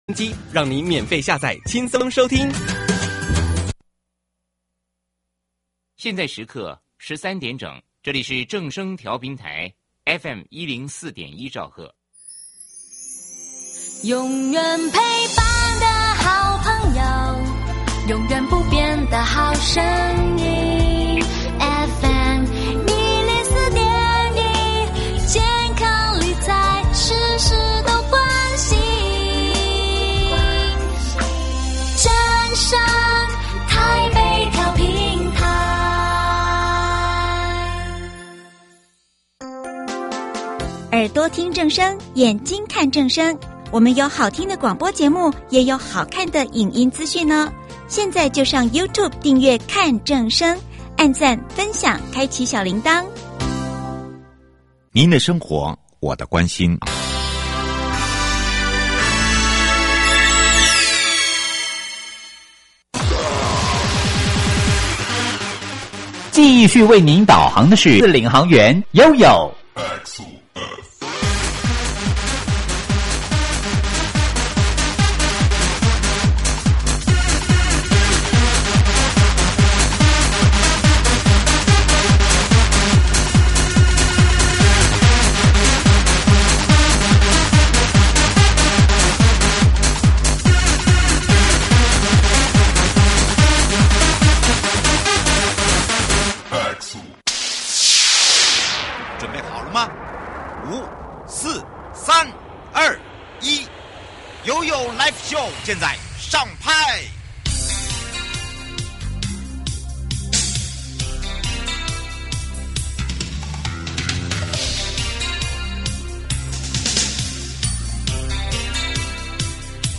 受訪者： 營建你我他 快樂平安行~七嘴八舌講清楚~樂活街道自在同行!(四) 主題：打造樂活街道，市民與城市同步